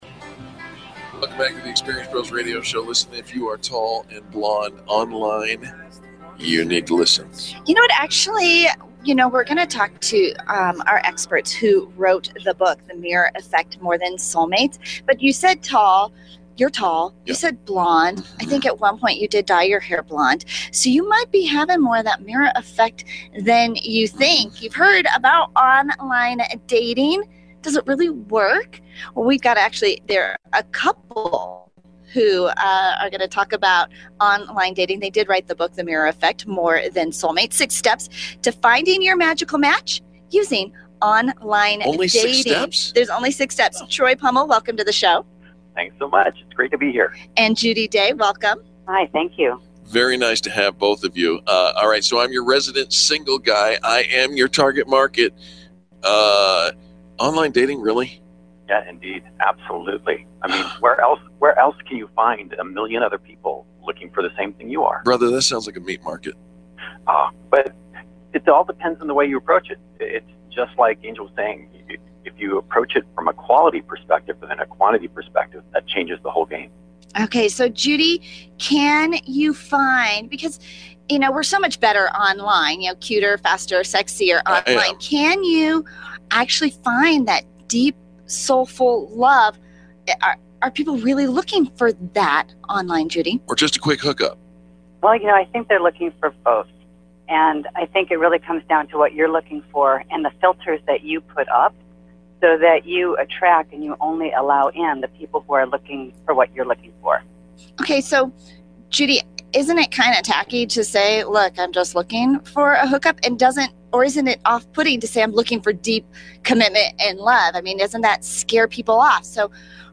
Interview with Experience Pros Radio Show…
Experience Pros Radio Show Interview - Feb 2015